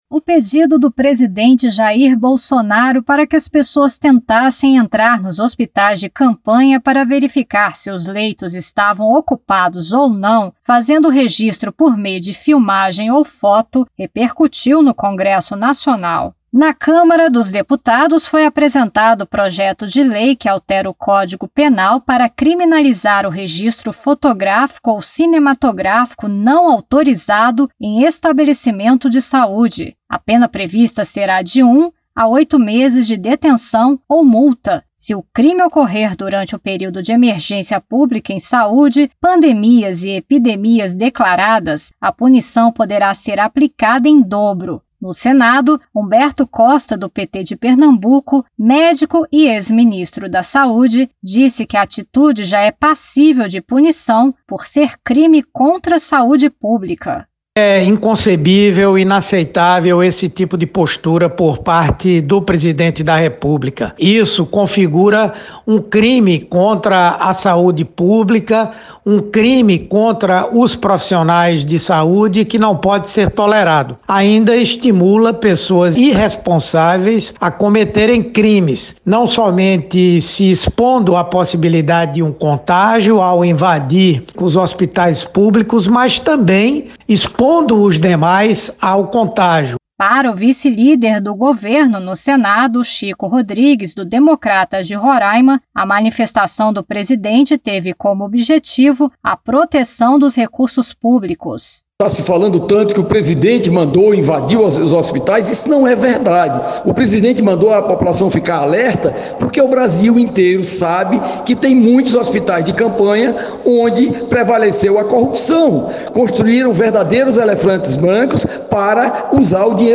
O pedido do presidente da República para que população entre nos hospitais de campanha e fiscalize a ocupação dos leitos repercutiu no Congresso Nacional. O senador Humberto Costa (PT-PE), médico e ex-Ministro da saúde, classificou a atitude como criminosa. O vice-líder do Governo, Chico Rodrigues (DEM-RR), disse que a ideia é fiscalizar o uso dos recursos públicos.
A reportagem